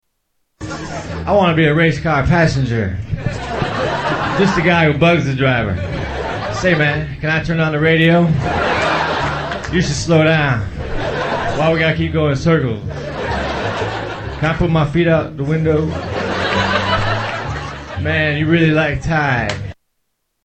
Category: Comedians   Right: Personal
Tags: Comedians Mitch Hedberg Sounds Mitchell Lee Hedberg Mitch Hedberg Clips Stand-up Comedian